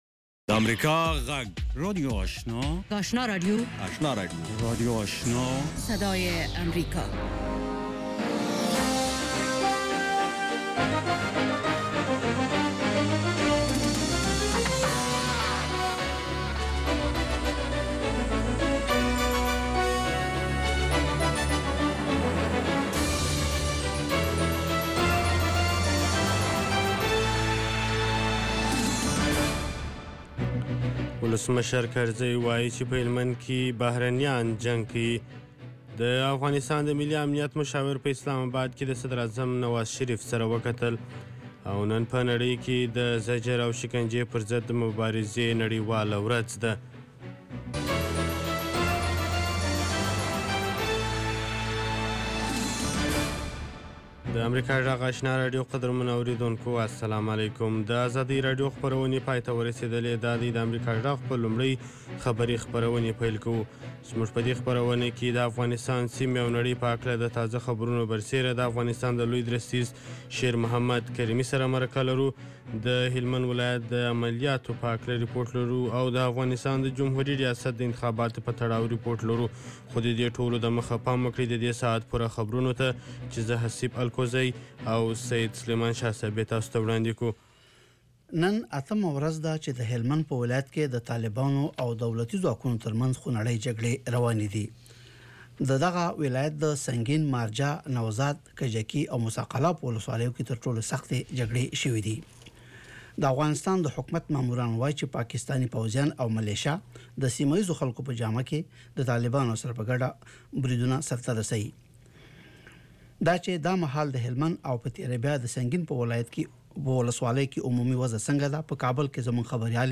ماښامنۍ خبري خپرونه
د اوریدونکو پوښتنو ته ځوابونه ویل کیږي. ددغه پروگرام په لومړیو ١٠ دقیقو کې د افغانستان او نړۍ وروستي خبرونه اورئ.